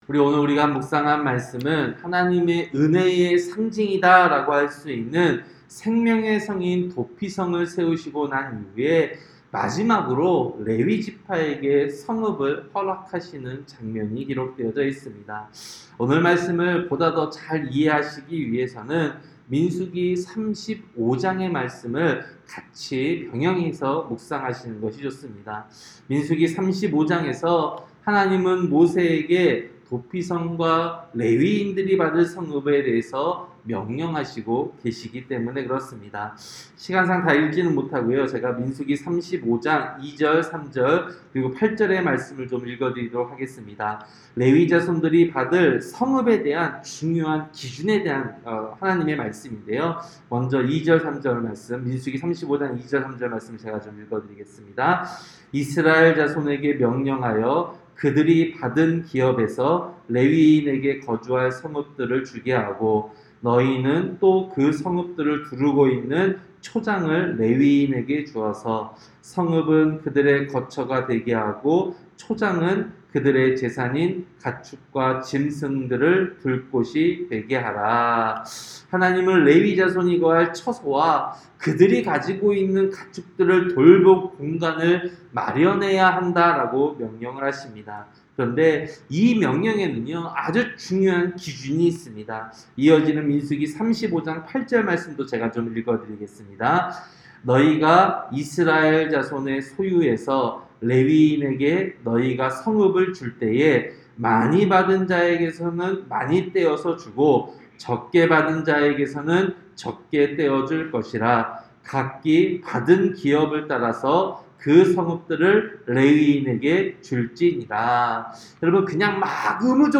새벽설교-여호수아 21장